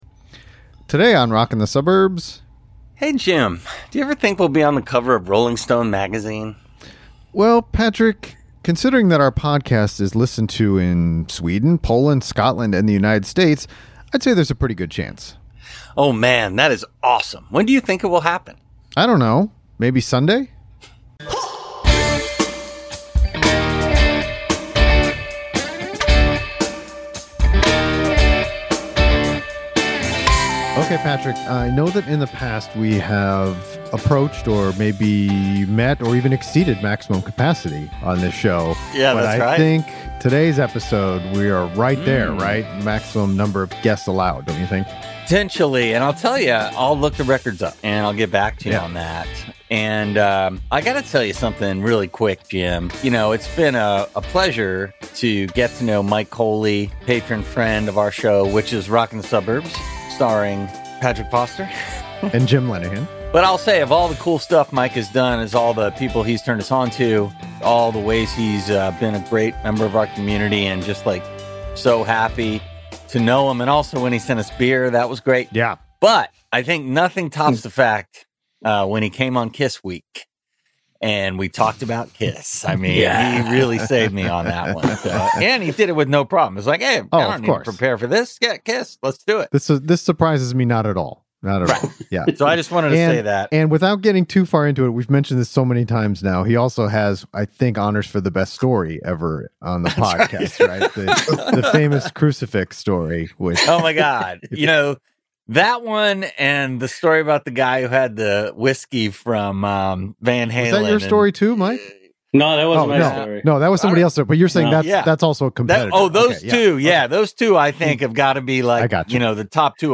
Maybe Sunday - Interview